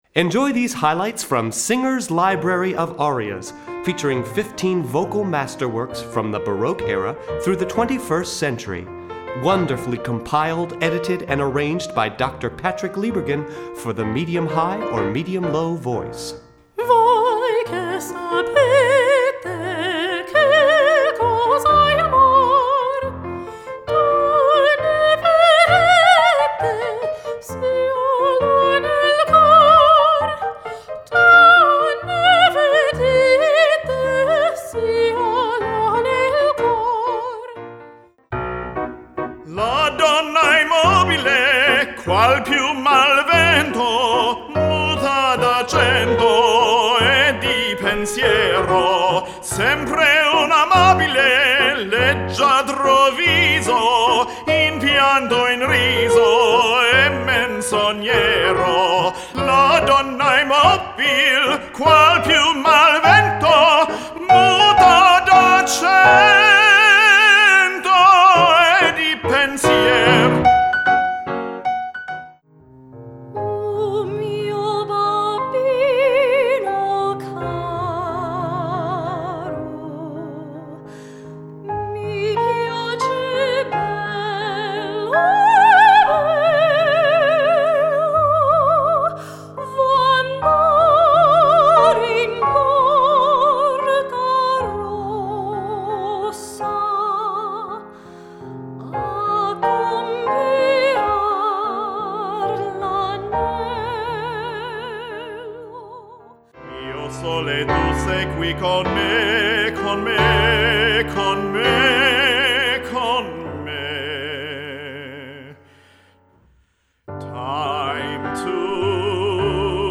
Voicing: Medium-High Voice